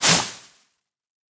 Звуки мусора